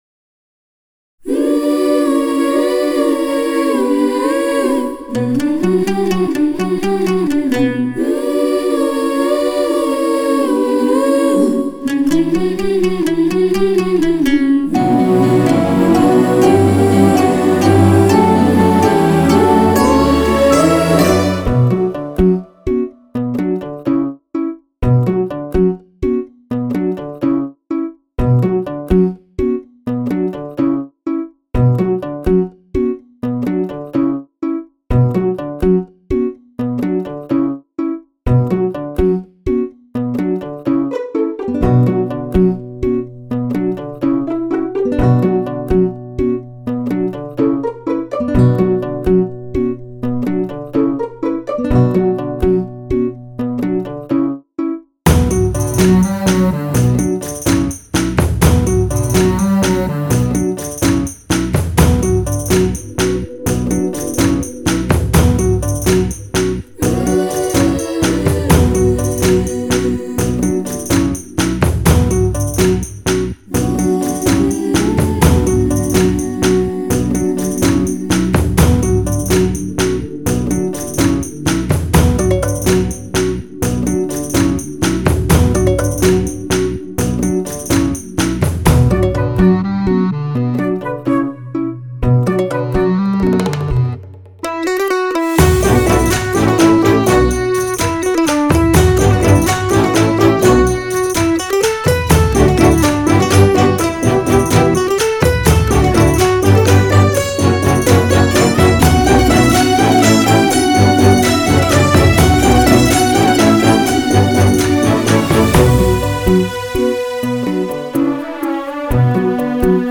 Singer: Karaoke Version